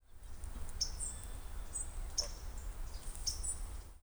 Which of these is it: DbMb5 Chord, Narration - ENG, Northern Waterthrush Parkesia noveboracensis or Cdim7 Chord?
Northern Waterthrush Parkesia noveboracensis